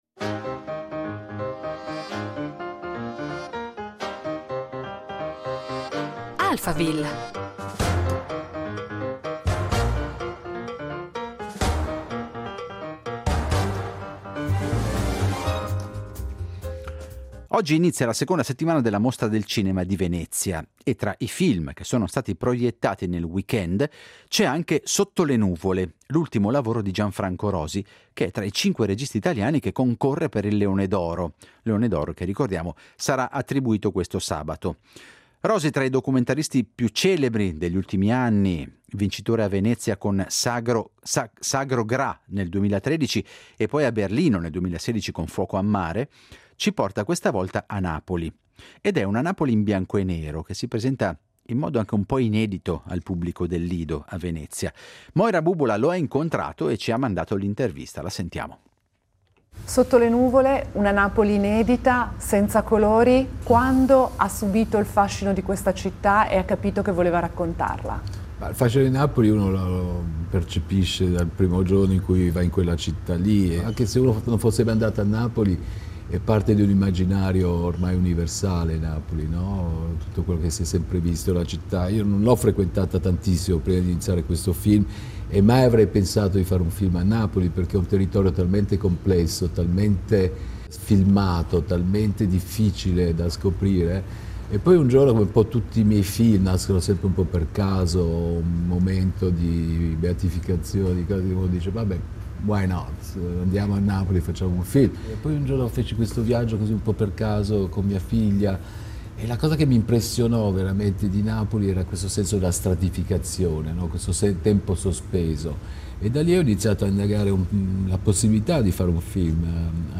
Il regista Gianfranco Rosi si racconta